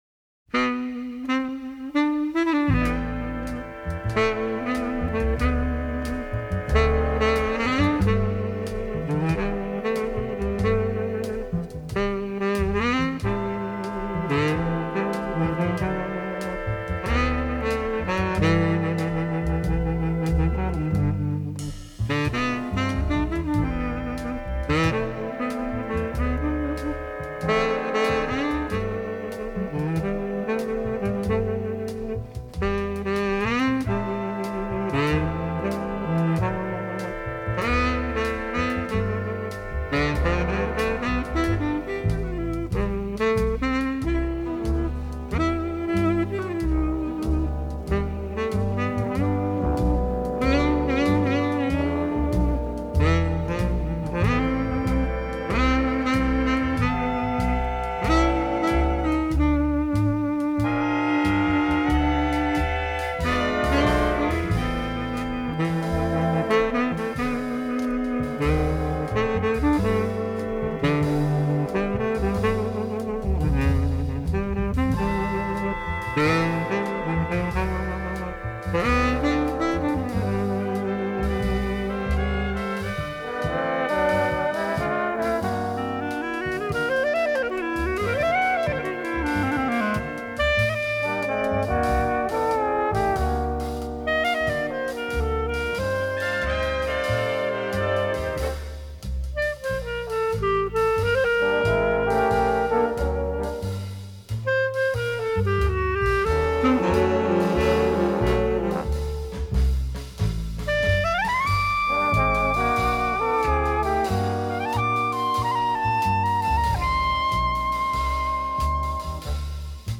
Big Band
• BALLAD (JAZZ)
• Baritone Sax
• Clarinet
• Trumpet
• Piano
• Bass
• Drums